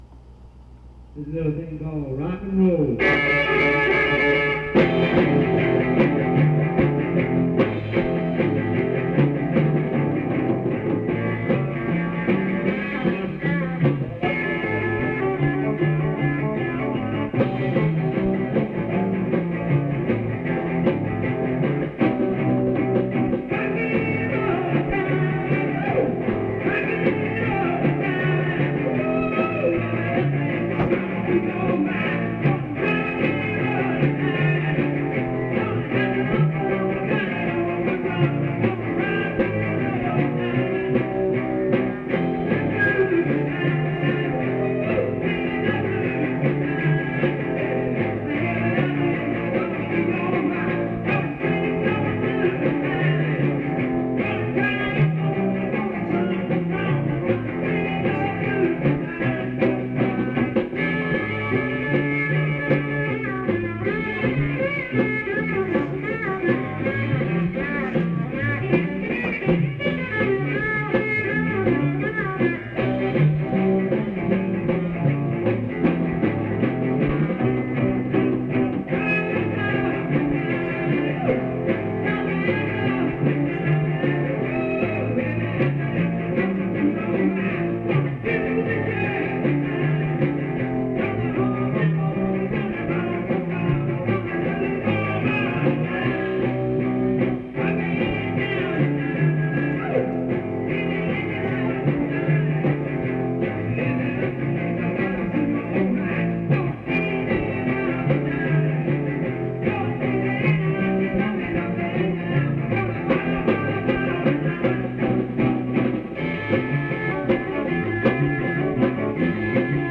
Vocal/drums